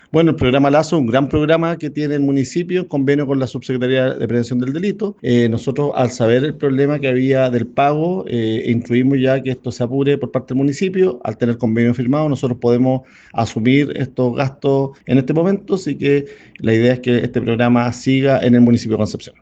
En ese sentido, esta última comuna evaluó los gastos, donde son 12 trabajadores los que prestan poyo al programa, donde el municipio decidió absorber esos gastos y pedir el reembolso a la Subsecretaría para no hacer esperar más a los trabajadores, señaló el alcalde de Concepción, Héctor Muñoz.
cuna-alcalde-concepcion.mp3